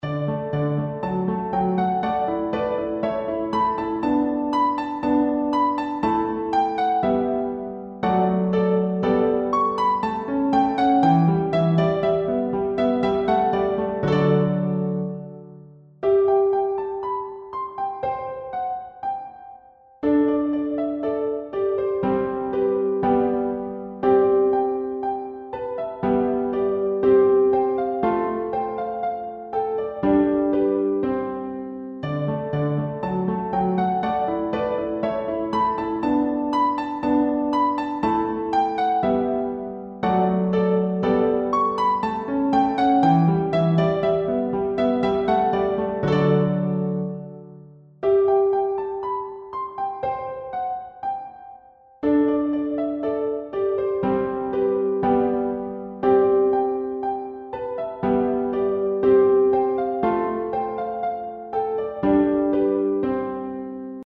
BGM
ショート明るい穏やか